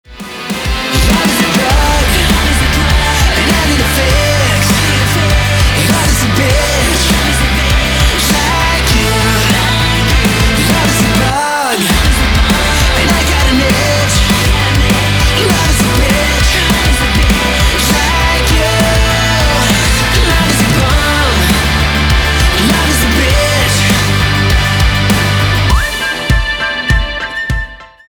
альтернатива
гитара , барабаны , громкие , свист